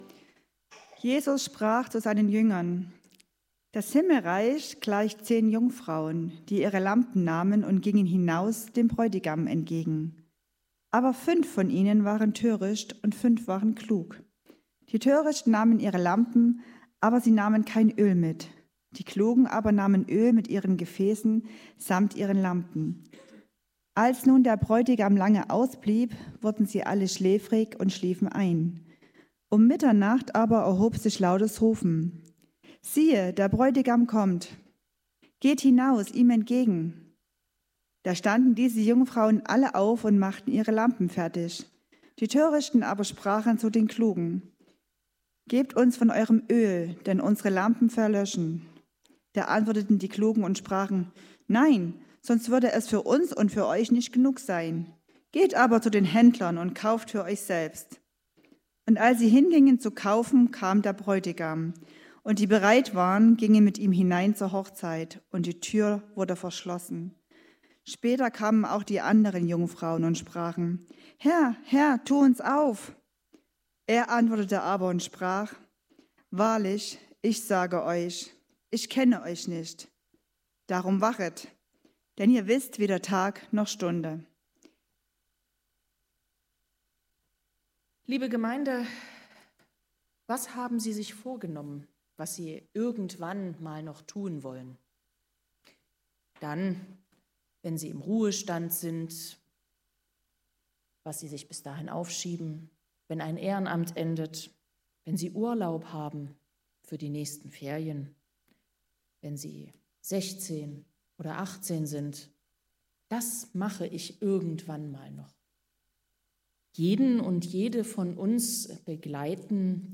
1-13 Gottesdienstart: Predigtgottesdienst Obercrinitz Jeder von uns hat eine begrenzte Zeit auf dieser Erde zur Verfügung.